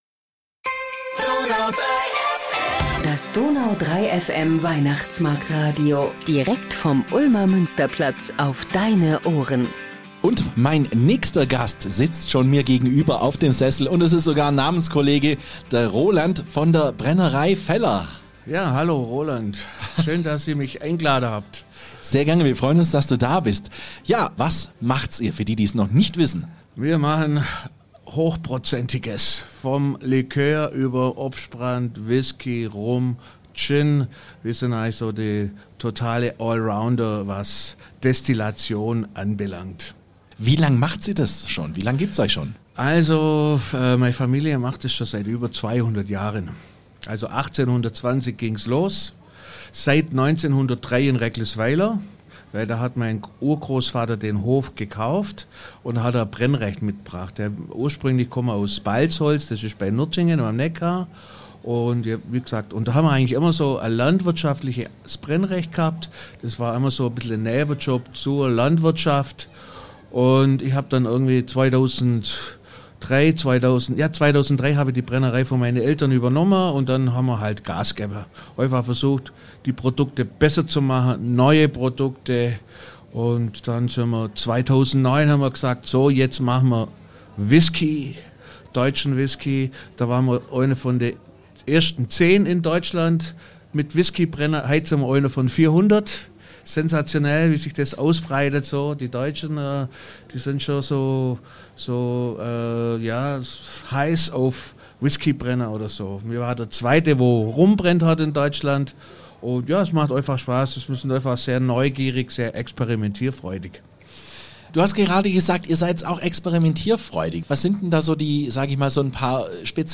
Im zweiten Teil hatten wir die Brennerei Feller, den Pfannenshop Angeletti, die Illerbuben, Süßwaren Högele und IMS Maurer in unserem Weihnachtsmarktstudio zu Gast.